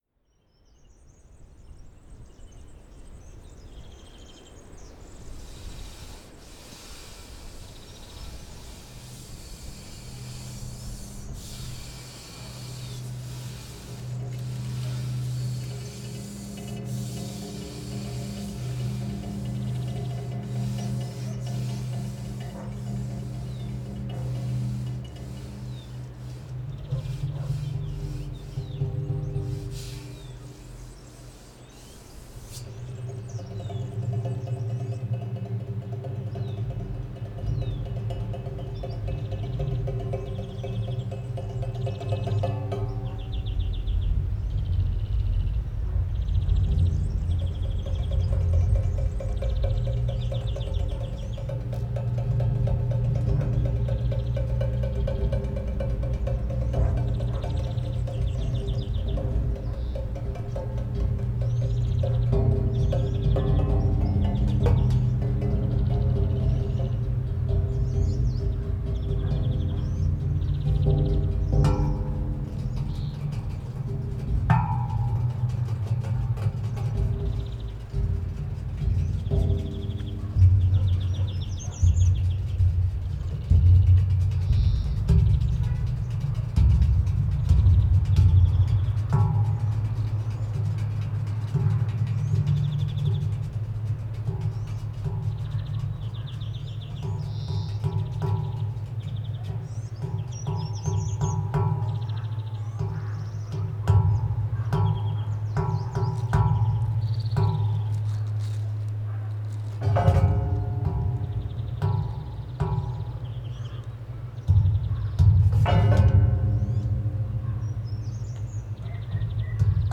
NODAR.00537 – Boa Aldeia, Farminhão e Torredeita: Grade tubular e varão no acesso à Capela da Nossa Srª da Saúde em Farminhão